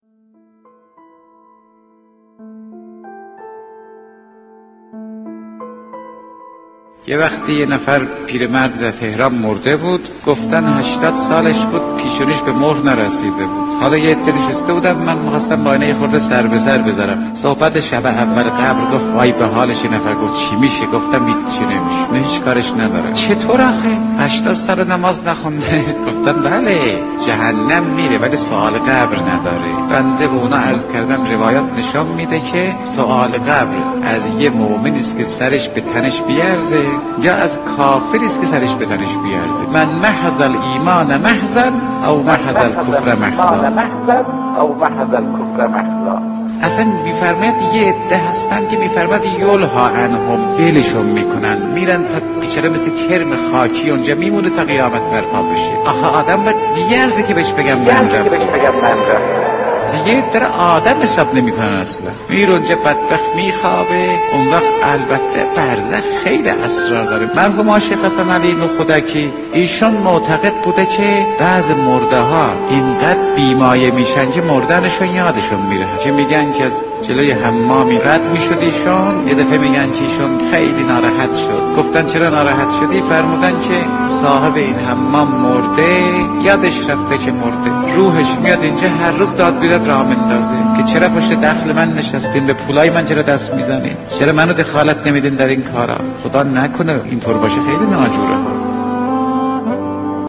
صحبت های حجت الاسلام فاطمی نیا درباره سوال و جواب قبر را در ادامه خواهید شنید.